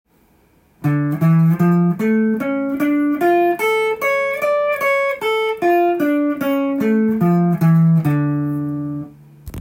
コードトーンｔａｂ譜
ＤｍＭ７（９）５弦ルート